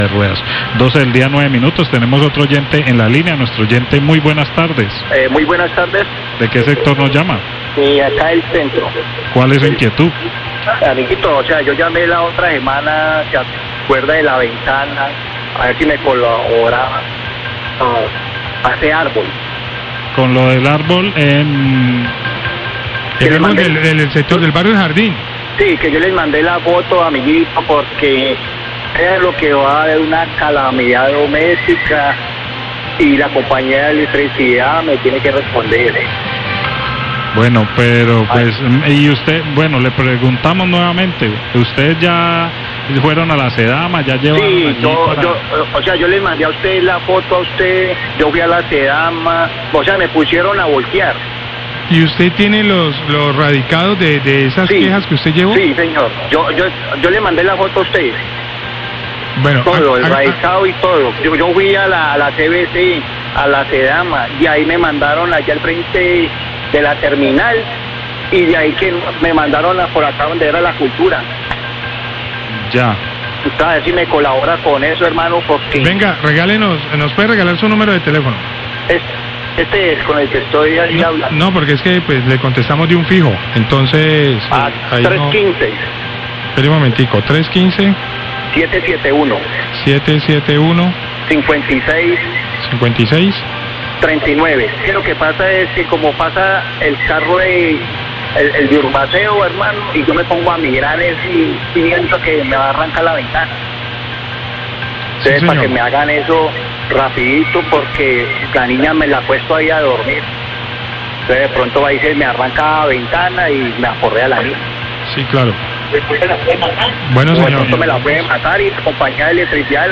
Oyente solicita la poda de un árbol que está frente a su casa y representa un peligro, La Cariñosa, 1209pm
Radio